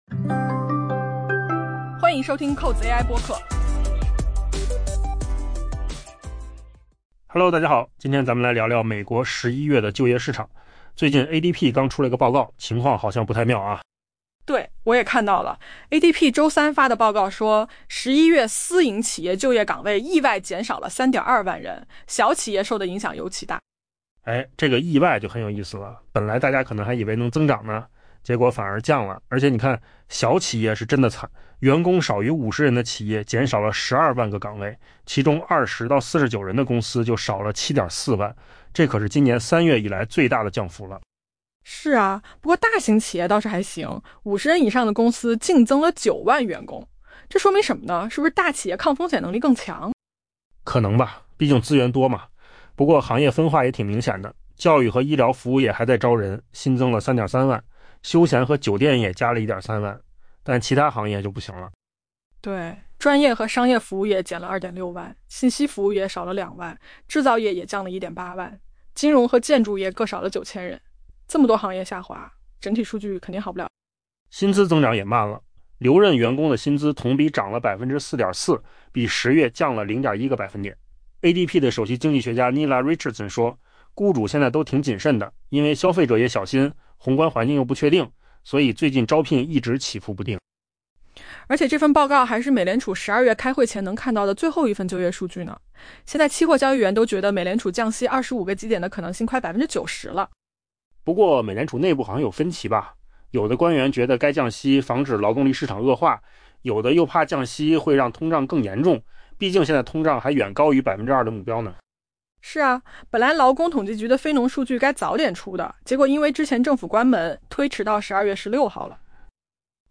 AI播客：换个方式听新闻 下载mp3
音频由扣子空间生成